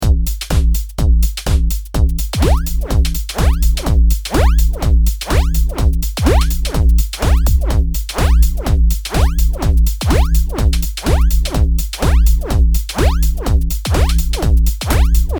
このウネリの主は、ゆったりとしたLFPOの流れによってコントロールされるCOMBフィルターです。
ちなみに251HzにしてQを上げるとこんな感じになります。